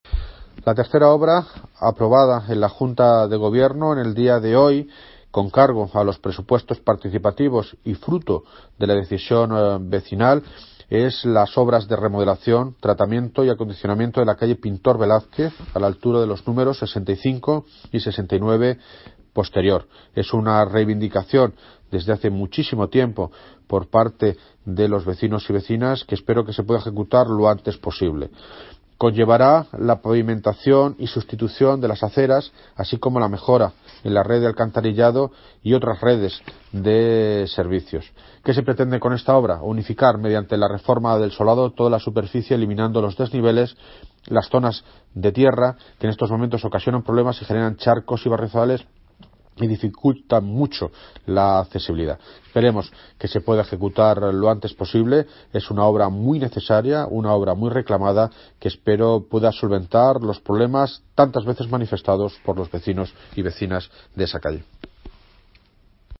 Audio - David Lucas (Alcalde de Móstoles) Sobre remodelación calle Pintor Velázquez